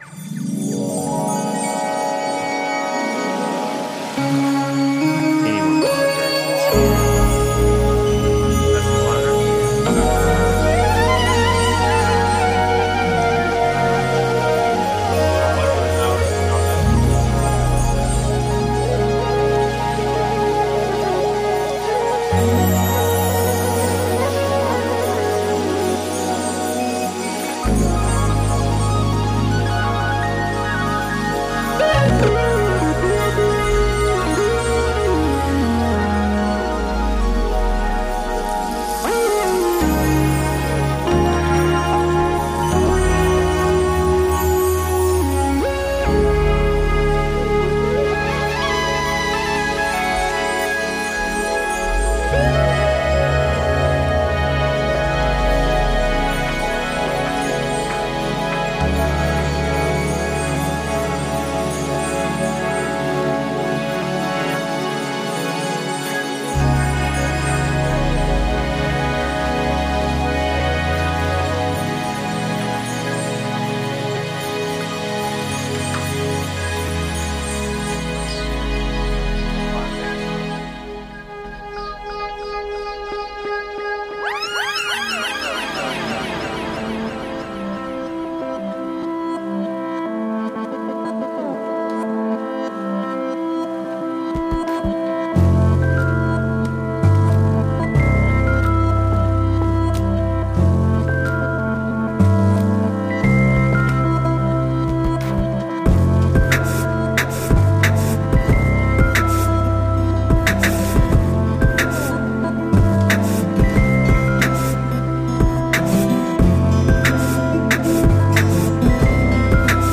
Beats Electronic